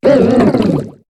Cri de Moyade dans Pokémon HOME.